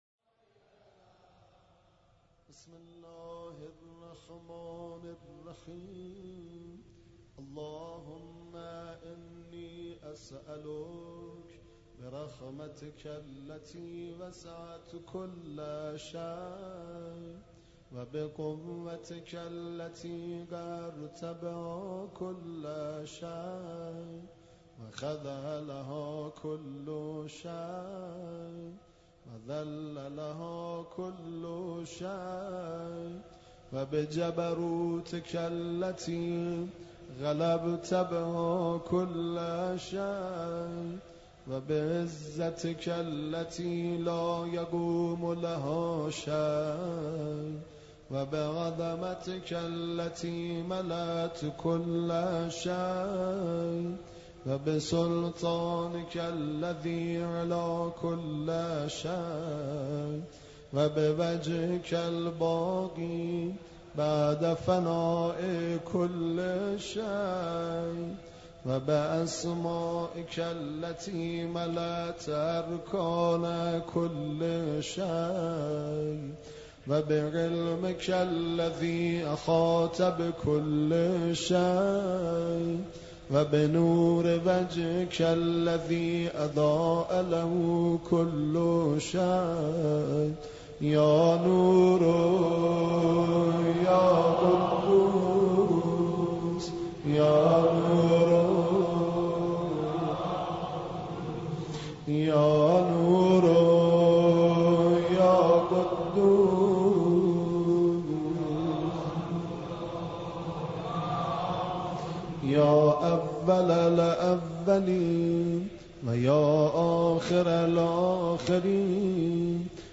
دعای کمیل